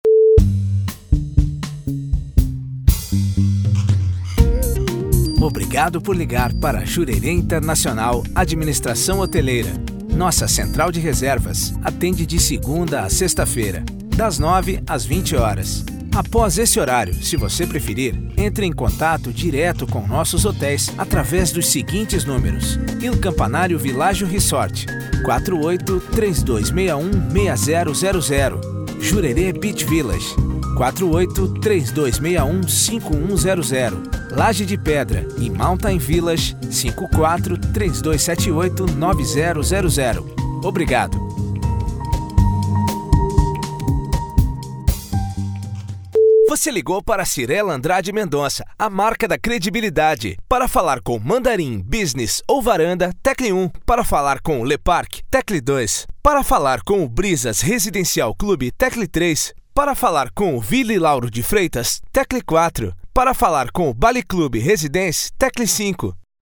• espera telefonica